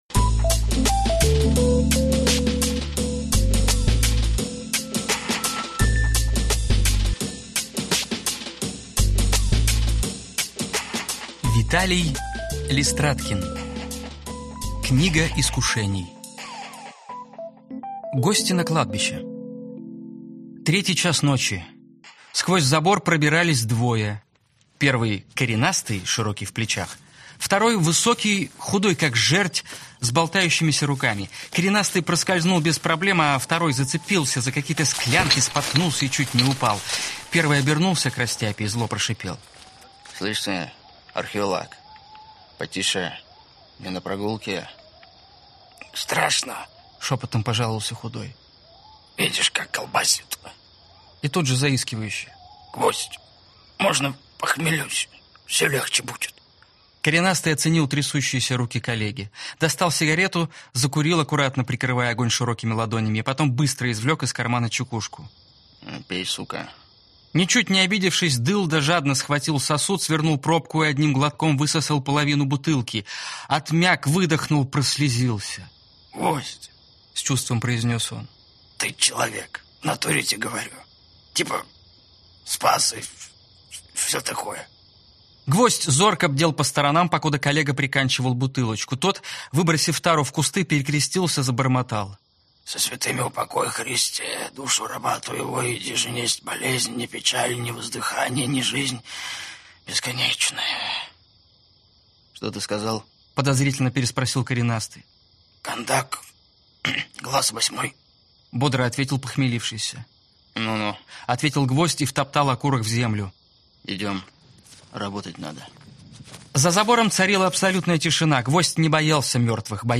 Аудиокнига Книга искушений | Библиотека аудиокниг